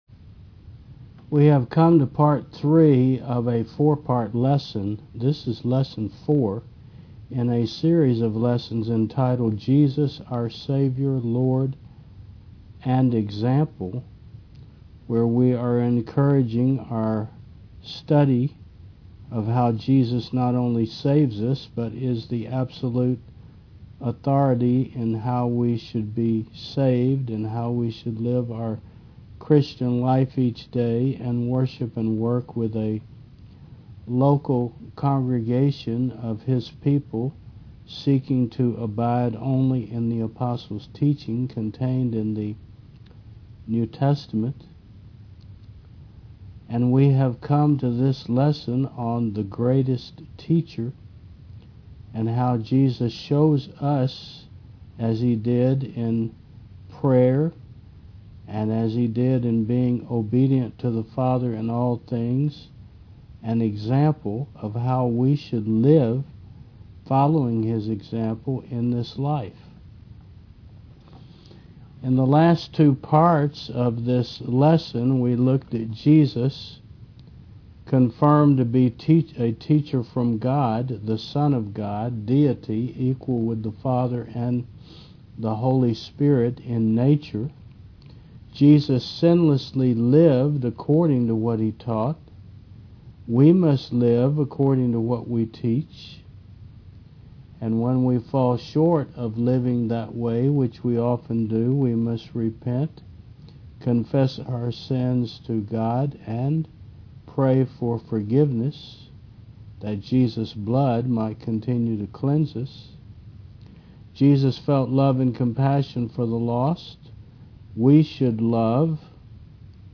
2025 Jesus Our Savior Lord and Example v2 4 The Greatest Teacher 3 Preacher